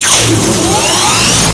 refuel-no-pause.wav